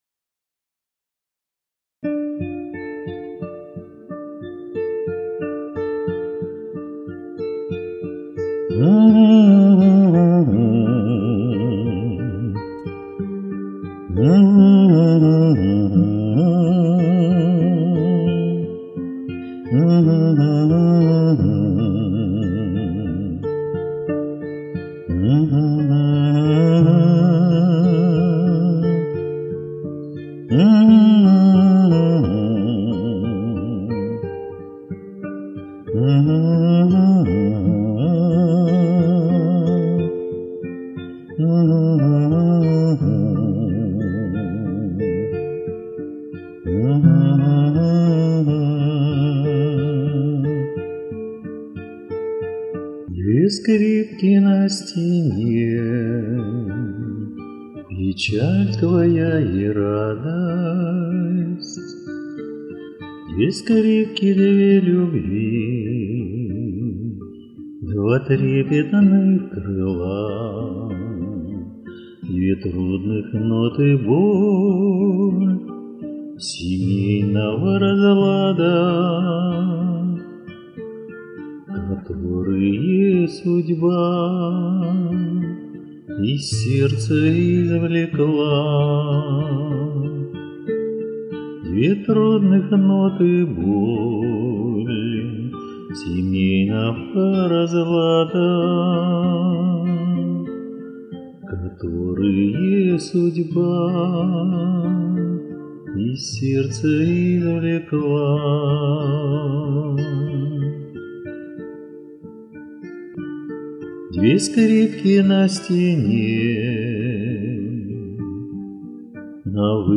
Скрипки.mp3